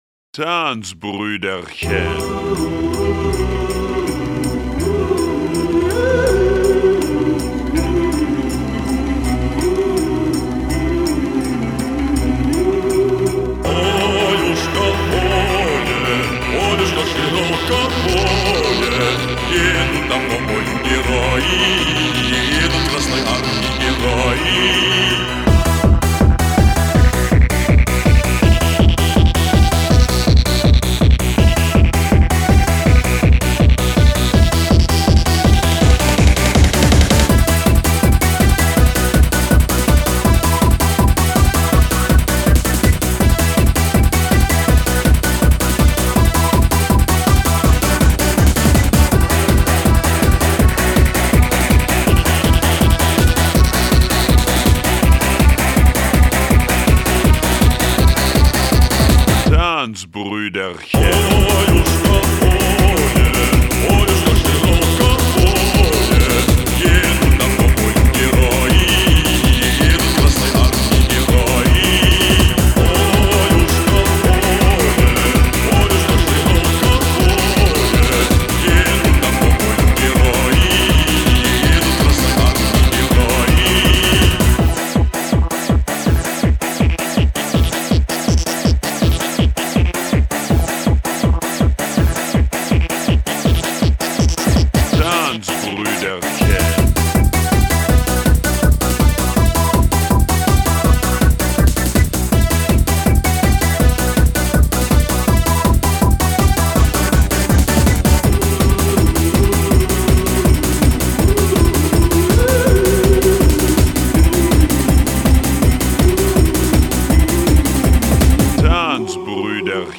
Rave